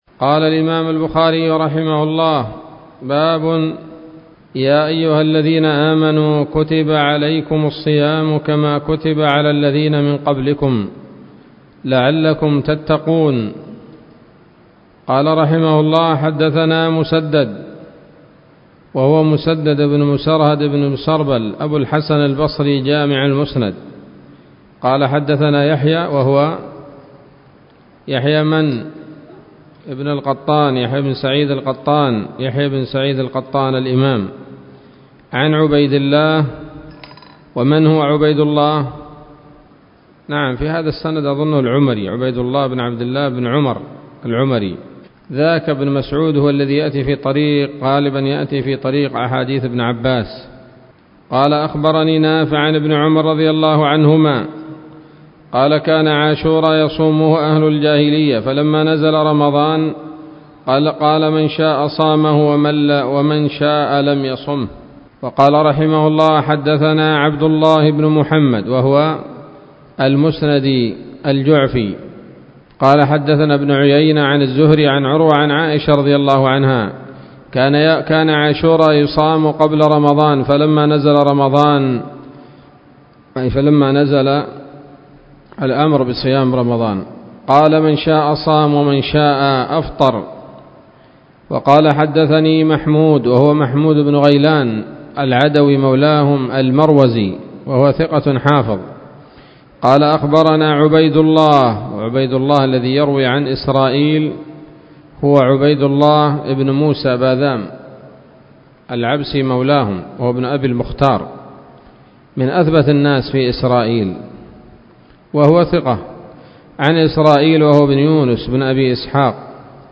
الدرس الحادي والعشرون من كتاب التفسير من صحيح الإمام البخاري